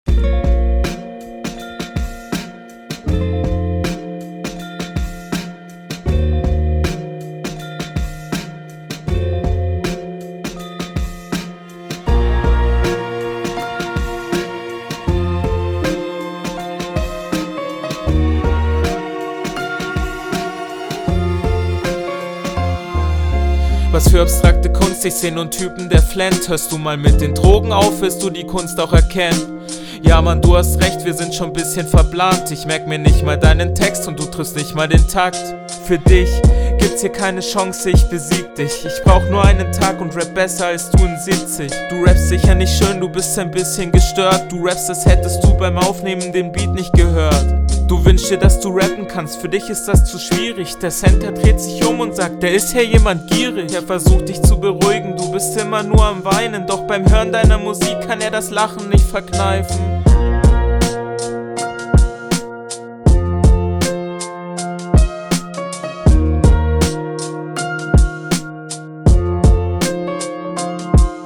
Dein Stimmeinsatz klingt sehr gelangweilt, was der Runde nicht guttut.